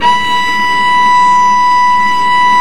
Index of /90_sSampleCDs/Roland - Brass, Strings, Hits and Combos/STR_Vc Marc&Harm/STR_Vc Harmonics